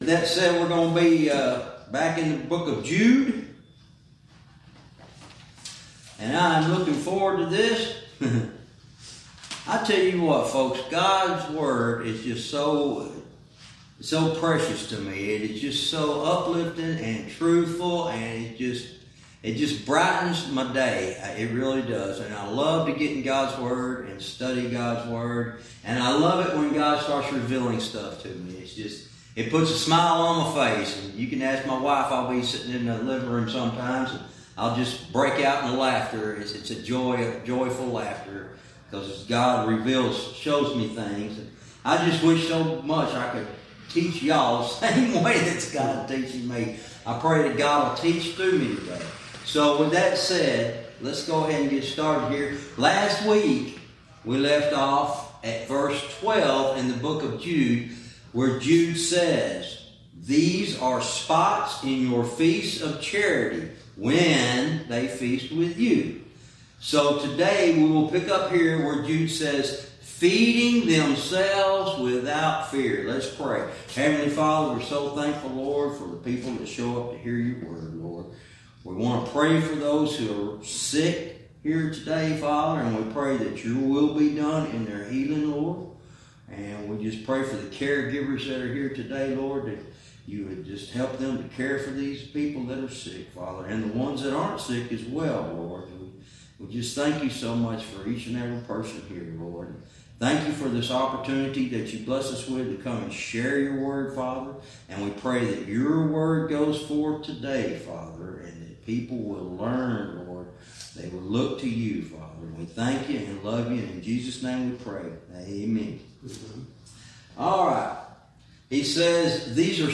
Verse by verse teaching - Lesson 47 Verse 12